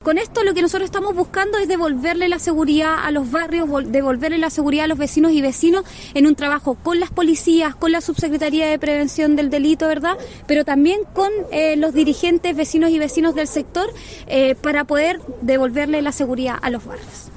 La delegada presidencial regional, Giovanna Moreira, se refirió al operativo y el fin de devolver la seguridad a los barrios.